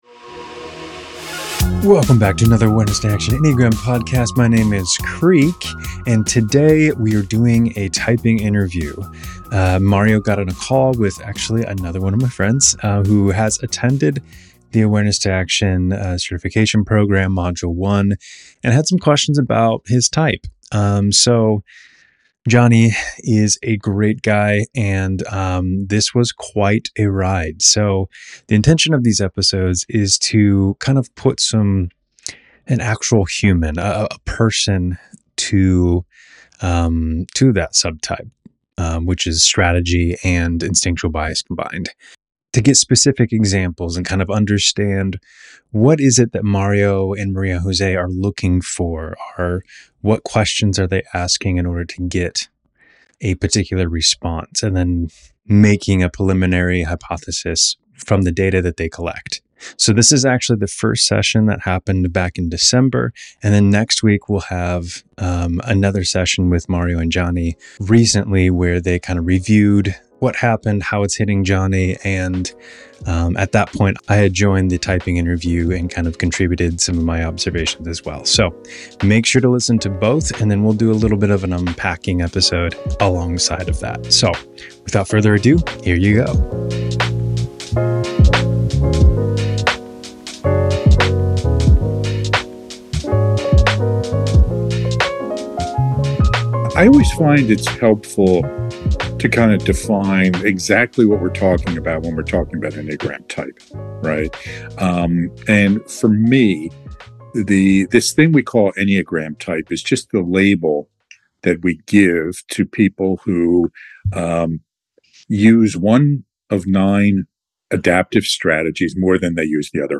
typing interview